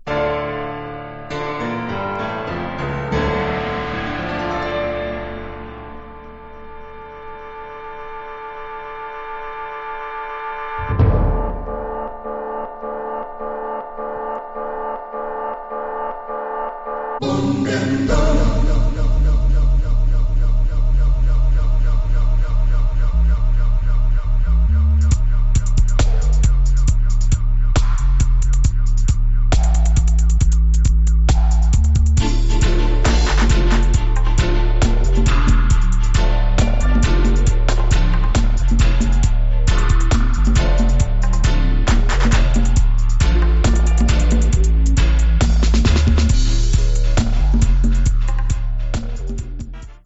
Genre: Dub Reggae.